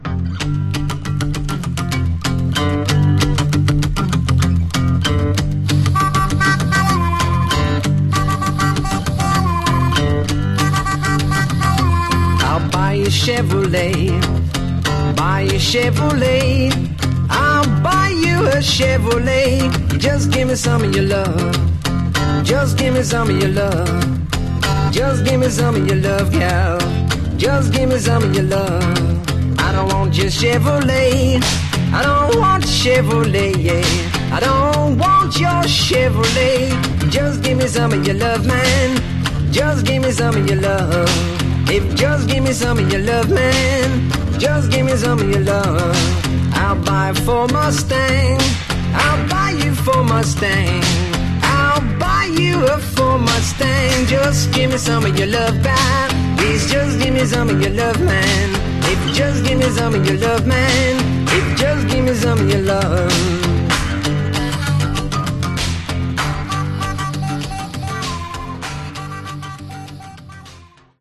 It has pristine Mint audio.